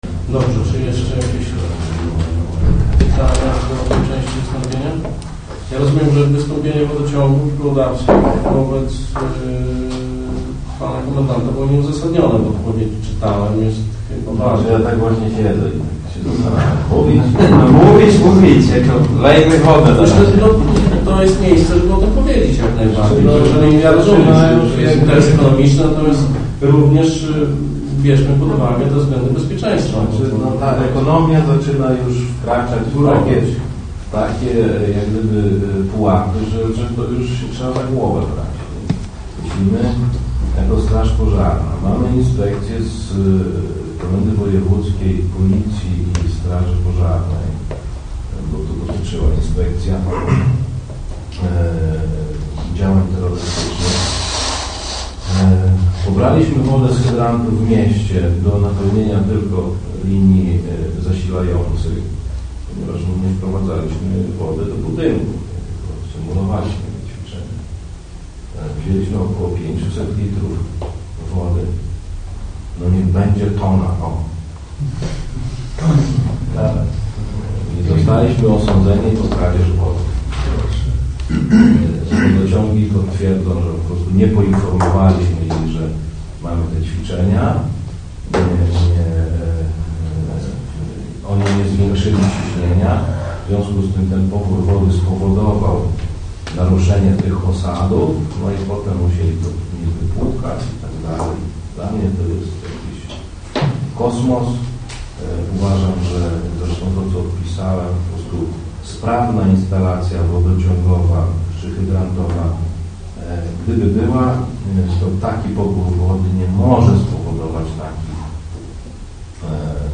fragment dyskusji podczas komisji bezpieczeństwa w powiecie